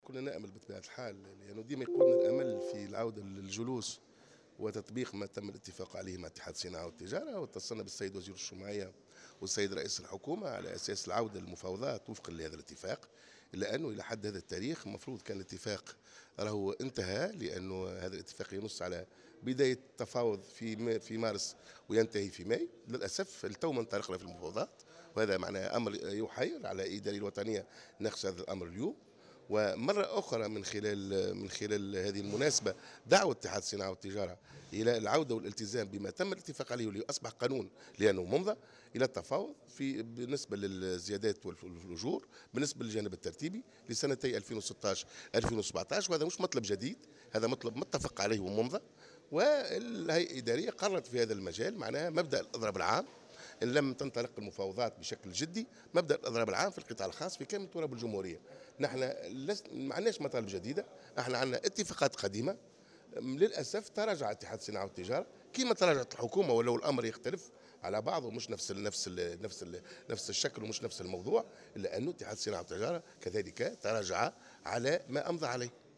وأضاف في تصريح لمراسلة "الجوهرة أف أم" على هامش اجتماع الهيئة الادارية لاتحاد الشغل الذي ينعقد بالحمامات أن اتحاد الصناعة والتجارة والصناعات التقليدية تراجع عن الاتفاقات السابقة، وعن الدخول في مفاوضات الزيادة في أجور القطاع الخاص بعنوان سنتي 2016 و2017.